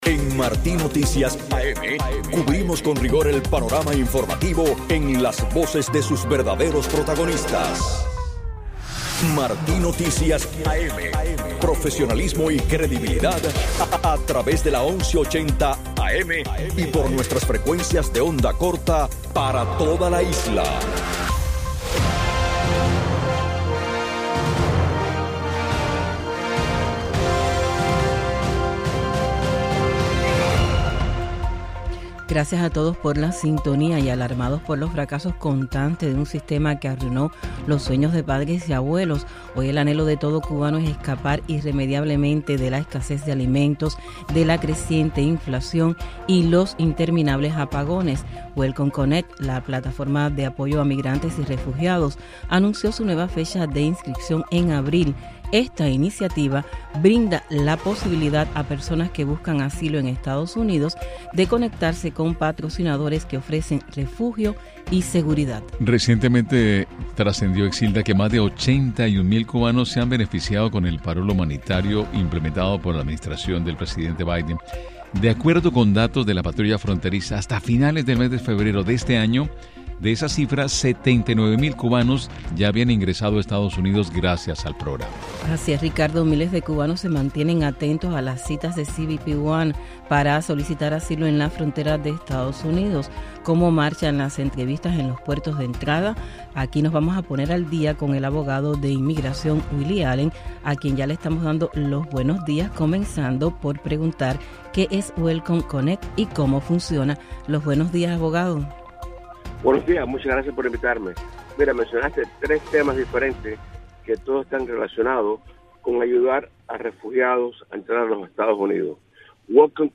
Declaraciones del abogado de inmigración